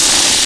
charge_loop1.ogg